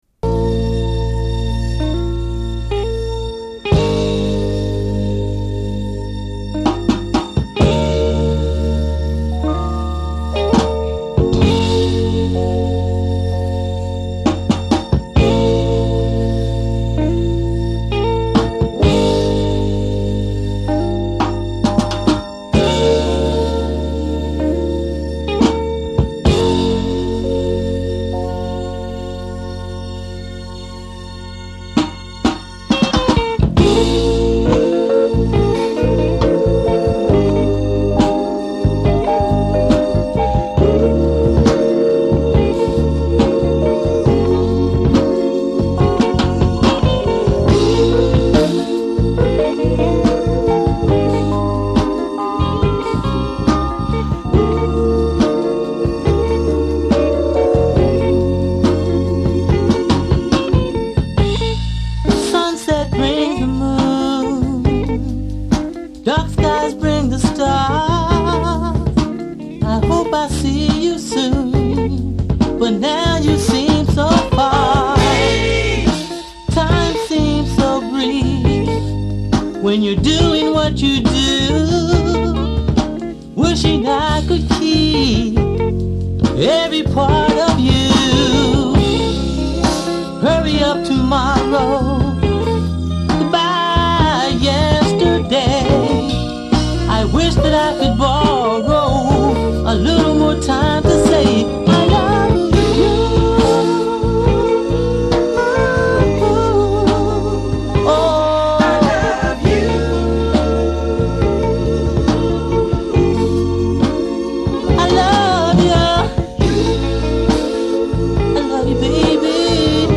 Genero: Blues